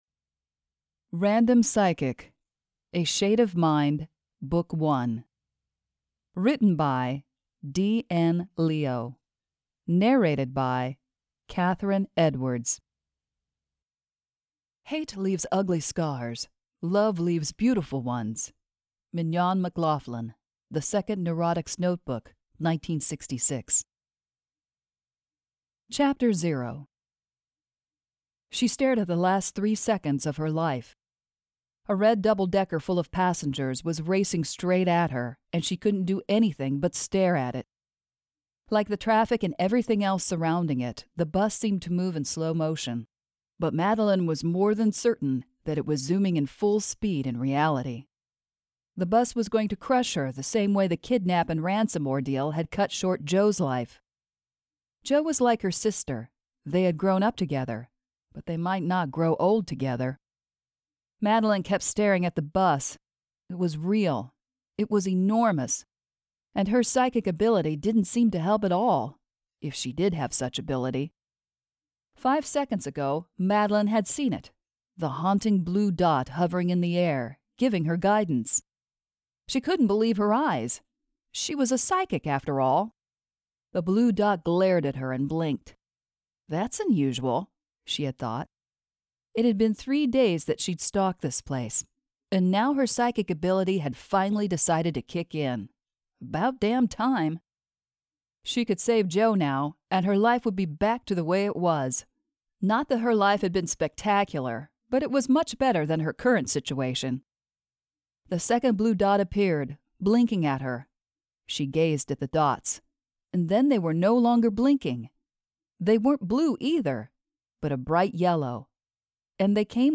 Curse of Soulmate - By D.N. Leo - The Complete Audiobook Series - 20 hours